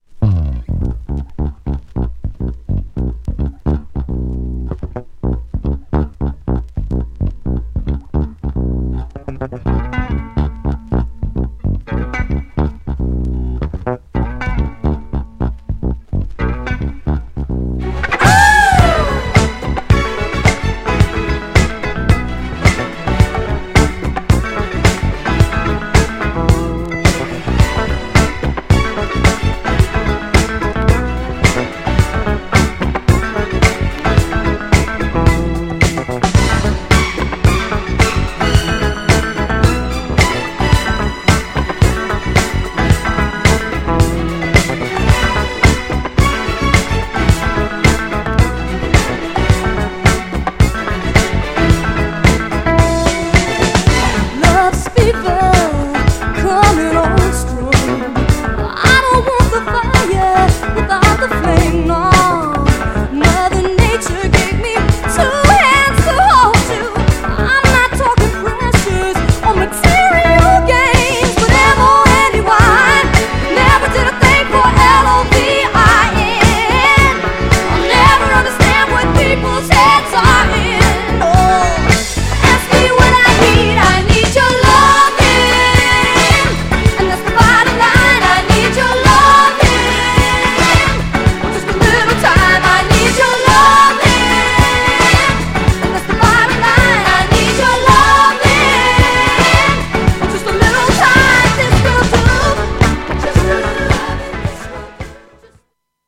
ど定番のDISCOヒット!!!
GENRE Dance Classic
BPM 106〜110BPM